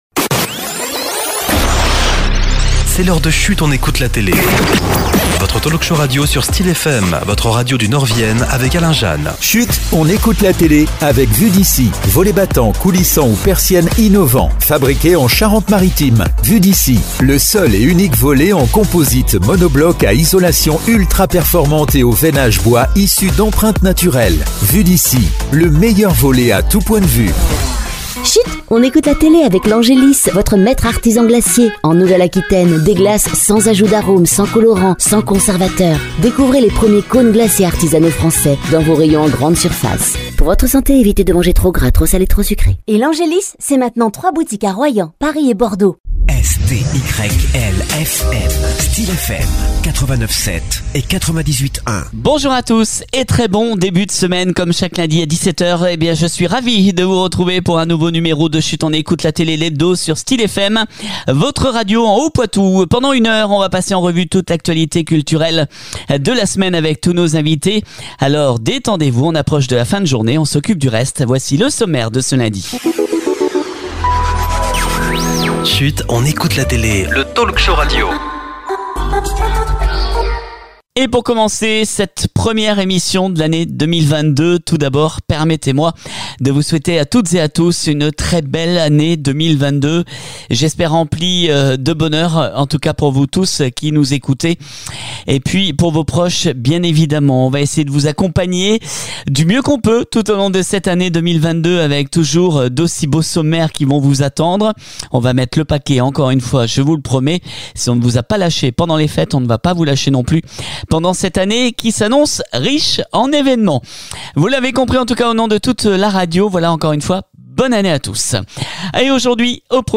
nos coups de coeur télé avec notre spécial “Sam” qui fait son retour sur TF1 avec son héroïne Natacha Lindinger et Charlotte Gaccio qui étaient nos invités